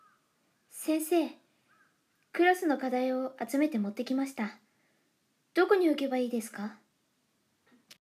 サンプルボイス 素直、真面目 【少女】